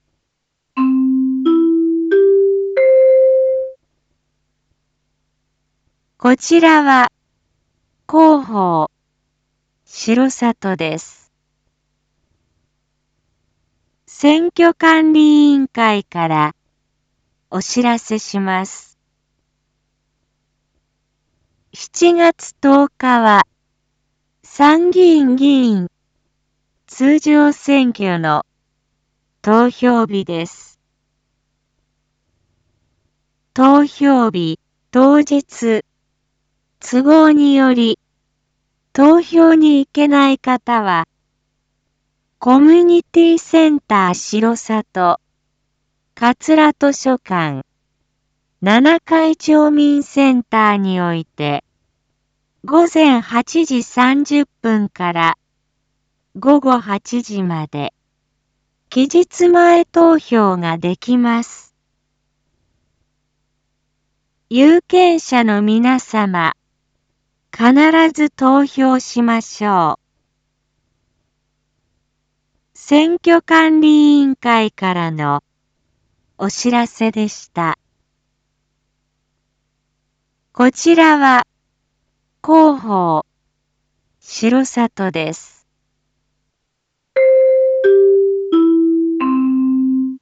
一般放送情報
Back Home 一般放送情報 音声放送 再生 一般放送情報 登録日時：2022-07-05 19:01:31 タイトル：参議院議員通常選挙（全地区期日前投票について） インフォメーション：こちらは広報しろさとです。